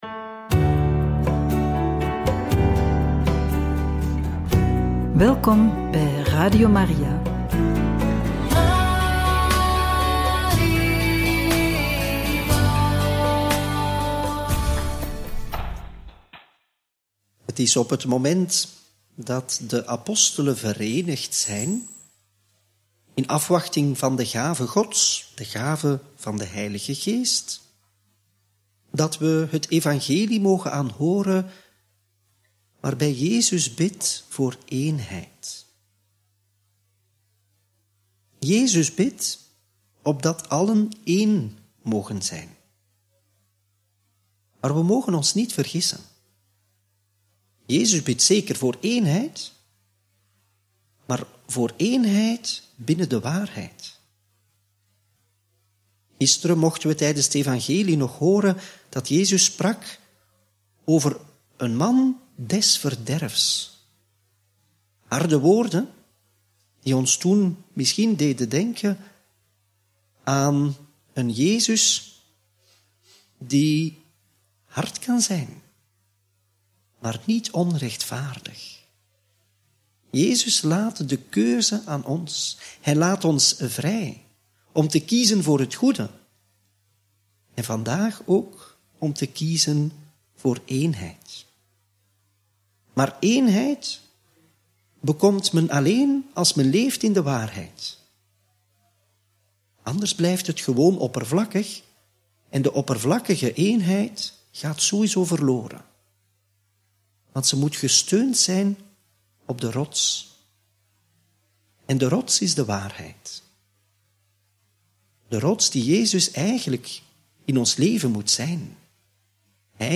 Homilie tijdens de Eucharistieviering van donderdag 16 mei 2024 – Radio Maria